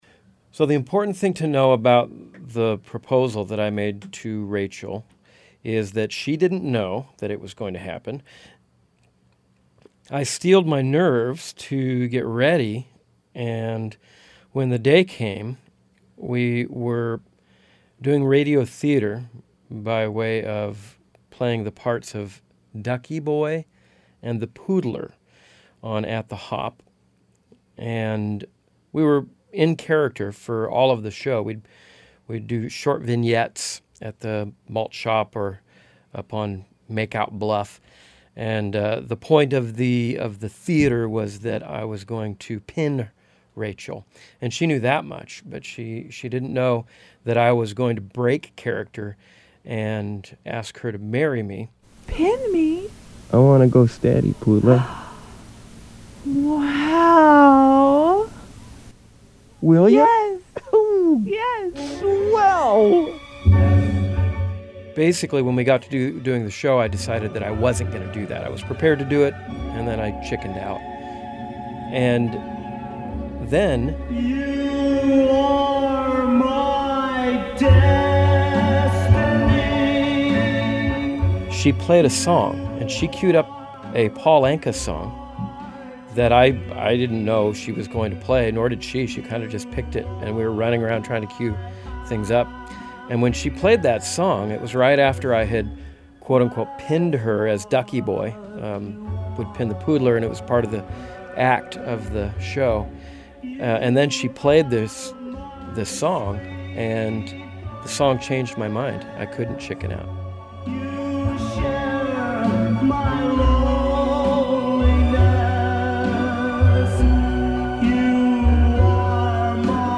With that, they put a needle on a record and left the building.